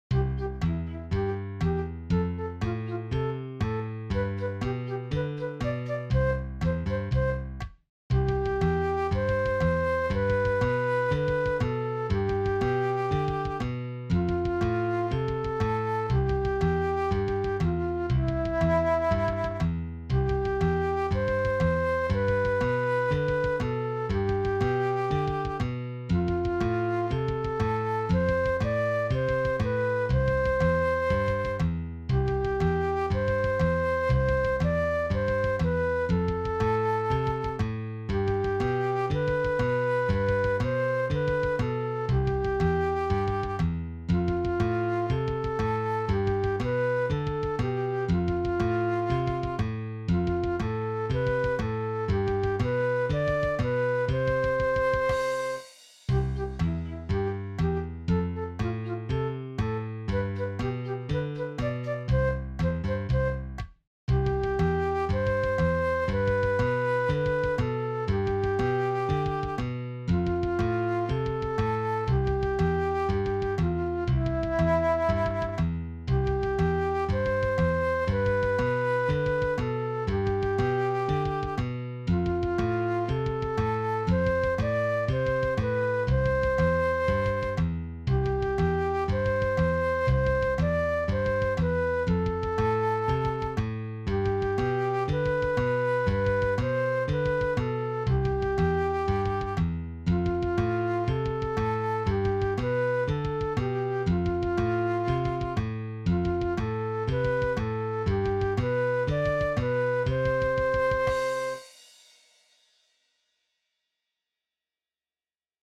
vi servirà  come base per cantare   o suonare